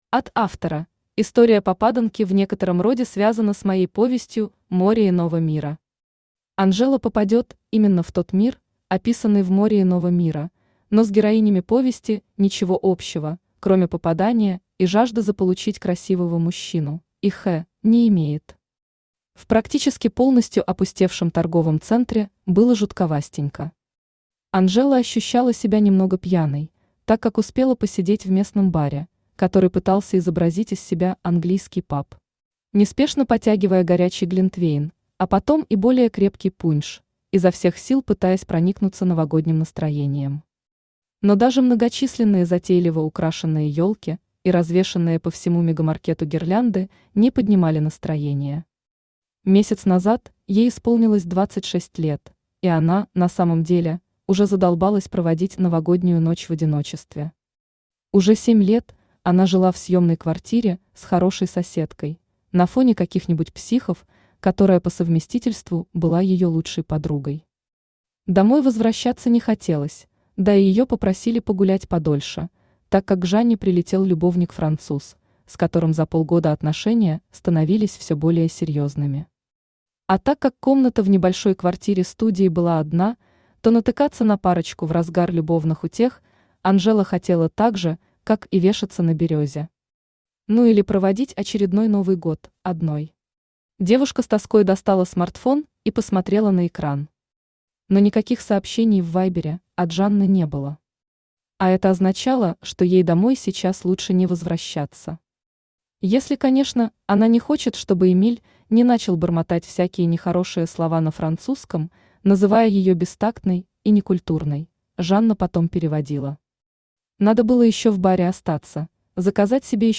Аудиокнига Снегурочка | Библиотека аудиокниг
Aудиокнига Снегурочка Автор Кристина Воронова Читает аудиокнигу Авточтец ЛитРес. Прослушать и бесплатно скачать фрагмент аудиокниги